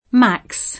Max [ceco makS; fr. makS; ingl.